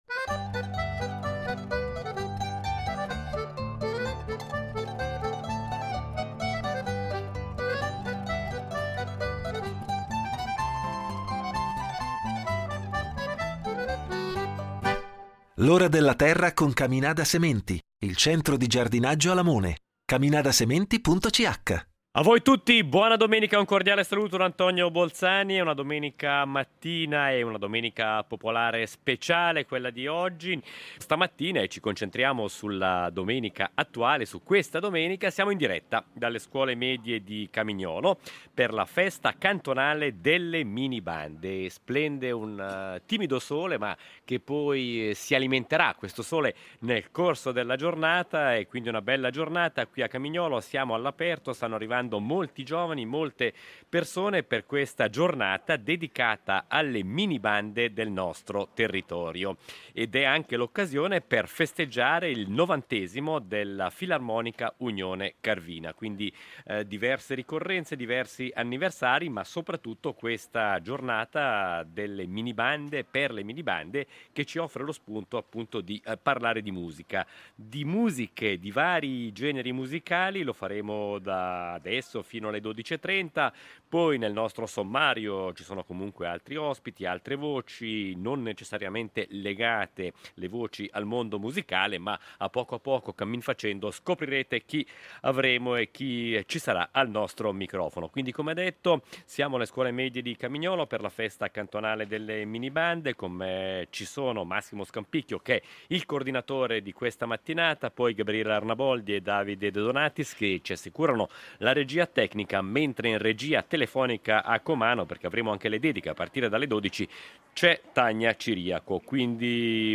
Musica maestro… con la Festa cantonale delle minibande: in diretta dalle Scuole Medie di Camignolo
Vi proponiamo, durante tutta la trasmissione, anche delle voci dei nostri archivi con una serie di interviste del passato ad alcuni dei protagonisti delle filarmoniche di un tempo, fra esperienze, obiettivi, esigenze, repertori e formazione dei giovani.
Nella seconda parte continuiamo a parlare del movimento bandistico ticinese e delle minibande con alcuni giovani musicisti impegnati alla Festa di Camignolo che ci raccontano la loro passione e il loro impegno per gli strumenti, per le bande e in generale per le attività musicali che seguono.